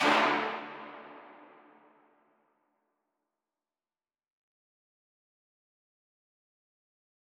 MDMV3 - Hit 11.wav